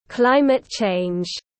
Biến đổi khí hậu tiếng anh gọi là climate change, phiên âm tiếng anh đọc là /ˈklaɪ.mət tʃeɪndʒ/.
Climate change /ˈklaɪ.mət tʃeɪndʒ/